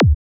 FLC-Kick-Parandroid-D.wav